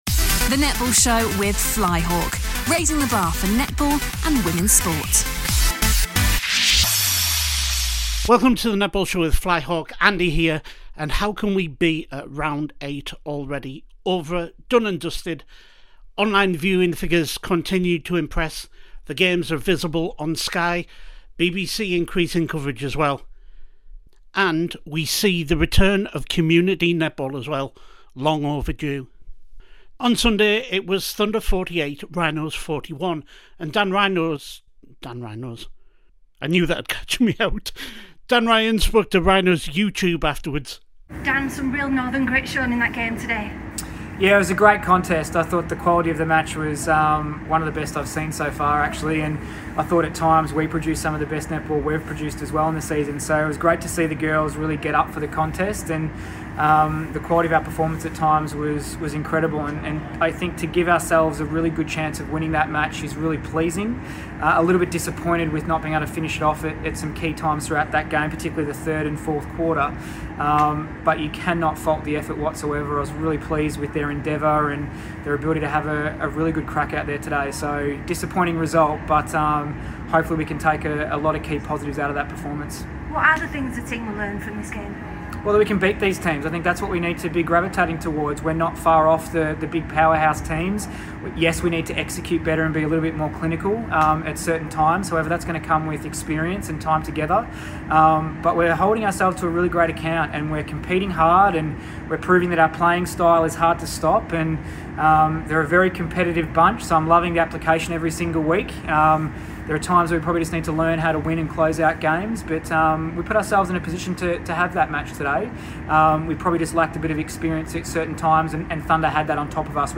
Back with more reaction from the NSL head coaches and players thanks to Sky Sports
Postmatch interviews are used with their permission